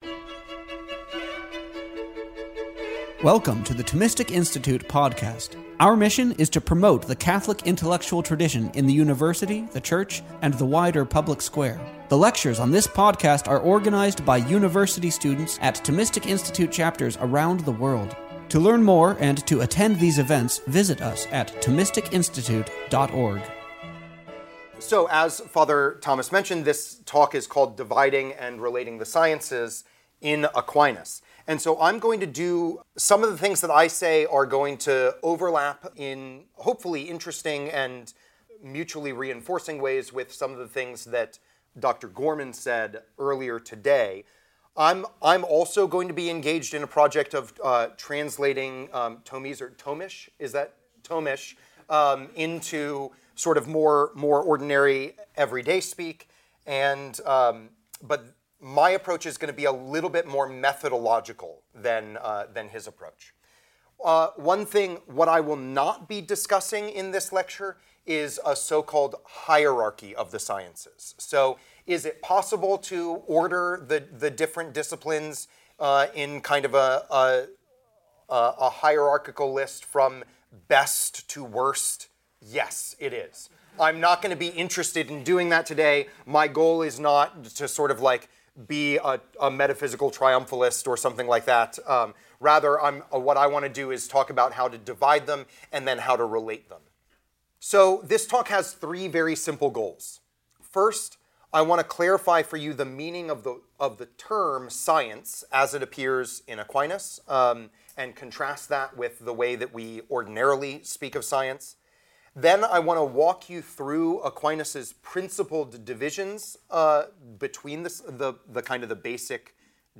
This lecture was given on June 11th, 2024, at Dominican House of Studies.